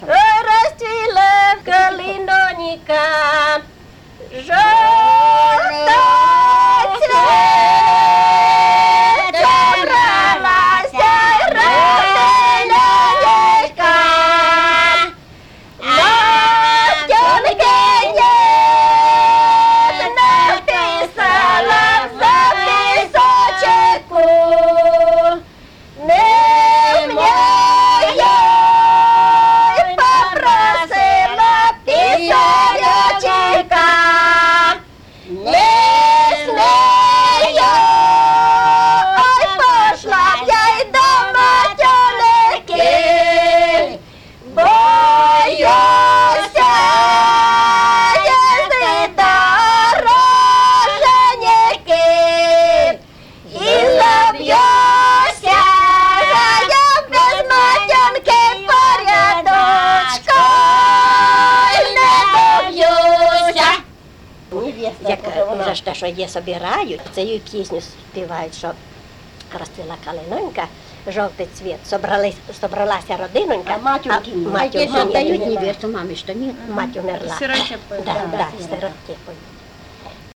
Воронежская область, Острогожский район, село Урыв
Манера исполнения свадебных песен резко отличает их от необрядовых музыкальных жанров, но близка к манере пения календарных песен. Используется специфический резкий звук, приемы глиссандо, элементы гукания — интонируемых возгласов на октаву вверх с переключением регистров, недопевания последнего слога стиха.
01 Свадебная песня «Ой